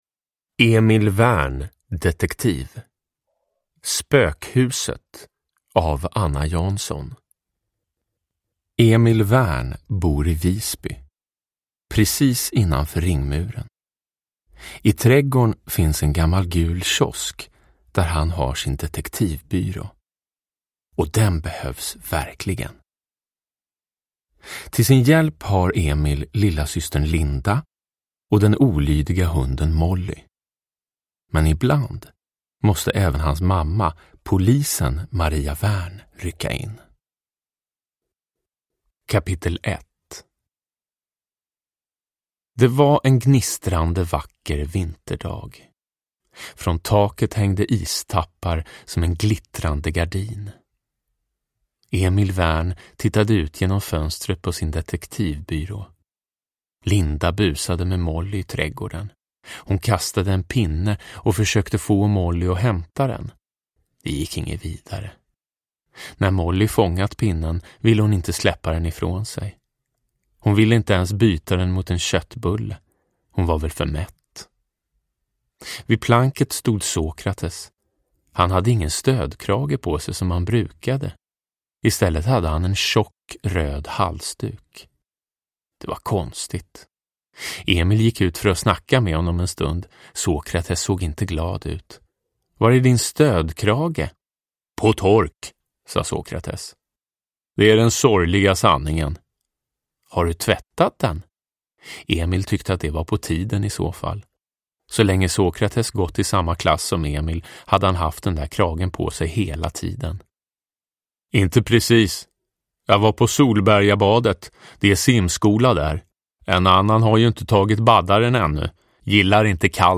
Spökhuset – Ljudbok
Uppläsare: Jonas Karlsson